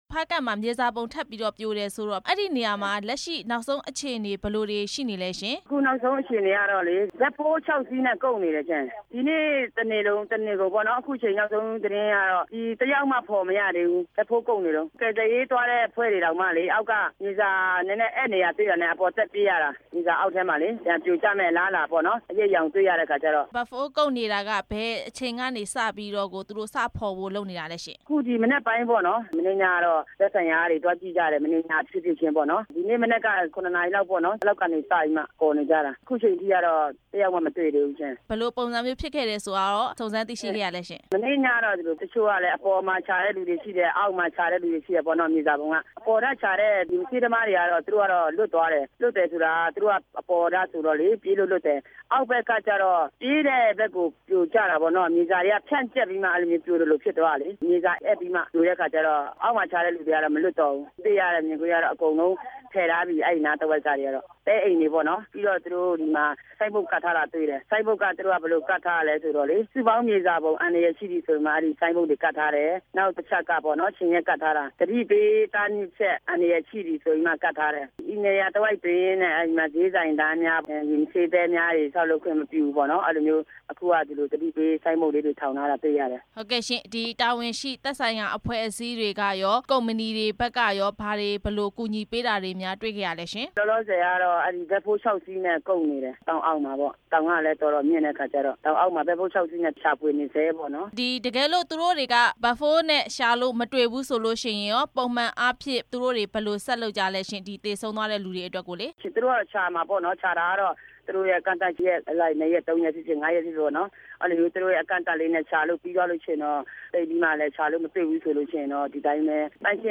ဖားကန့် မြေစာပုံ ထပ်မံပြိုကျ လူ ၃ဝ ကျော် ပိတ်မိနေတဲ့ အကြောင်း မေးမြန်းချက်